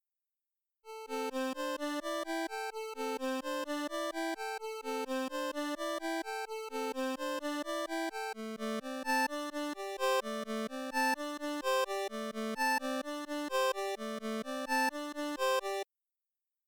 "intb" is a short, differently mixed loop of the main riff that was supposed to be used for... something, the hell if I remember.